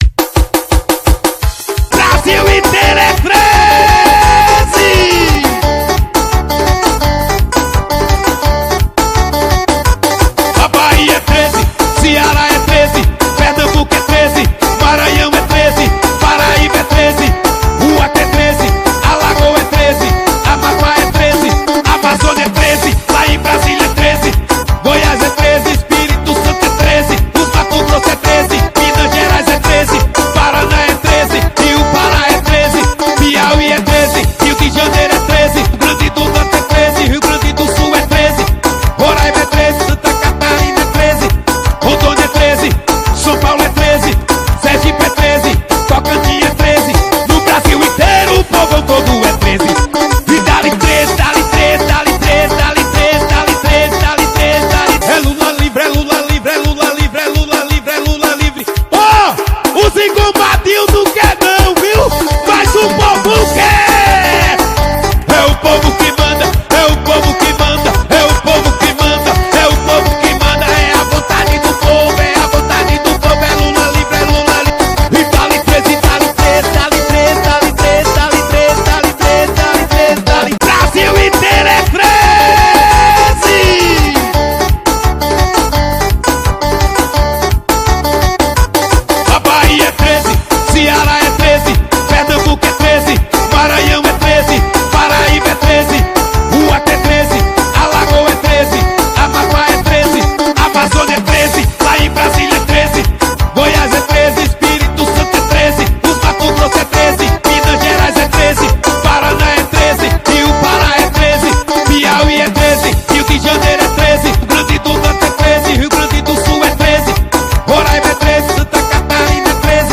2025-01-06 00:24:54 Gênero: Axé Views